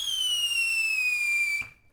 whistle.wav